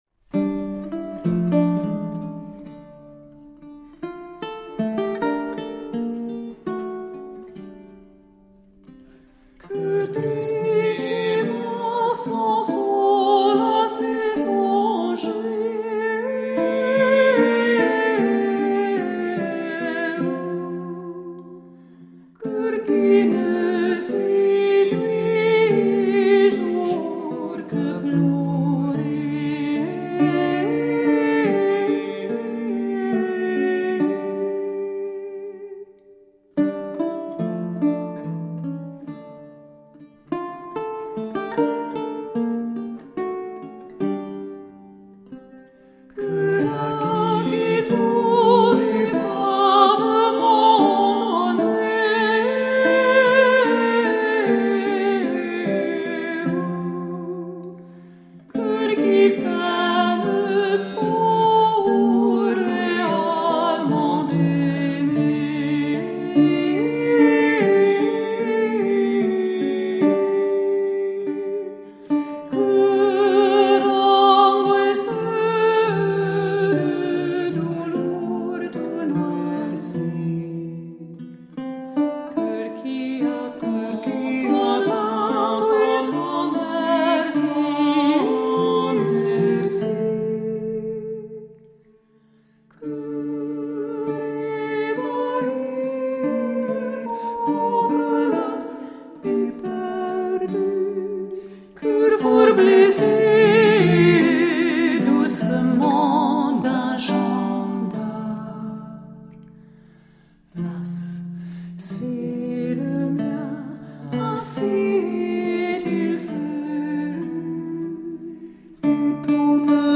Late-medieval vocal and instrumental music